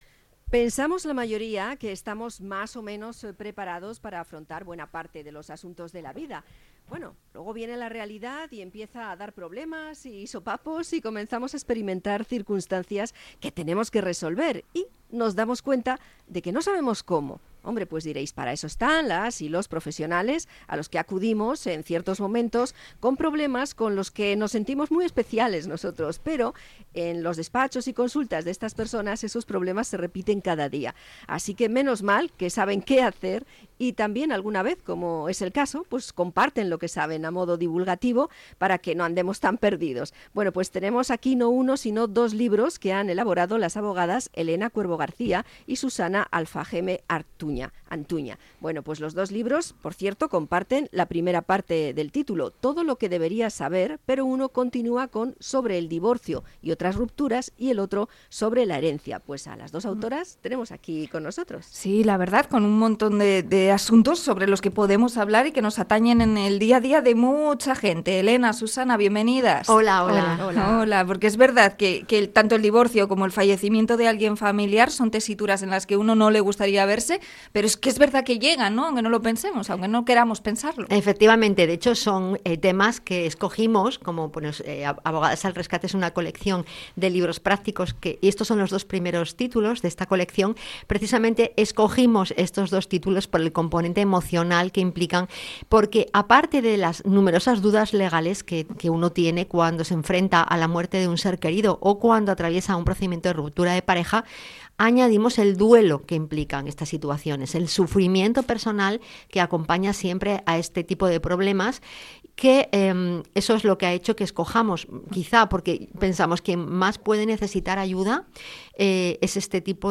Entrevista a abogadas sobre herencias y divorcios